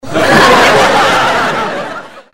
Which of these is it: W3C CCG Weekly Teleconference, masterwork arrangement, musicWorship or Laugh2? Laugh2